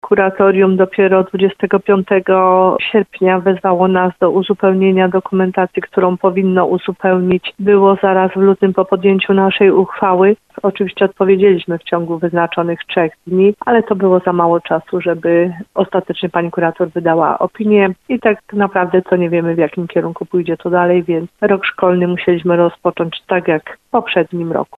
Procedura przeciągnęła się tak bardzo – bo aż do ostatnich dni sierpnia – że szkoła znów funkcjonować będzie tak jak w ostatnim roku – mówi wójt Małgorzata Gromala.